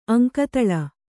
♪ aŋkataḷa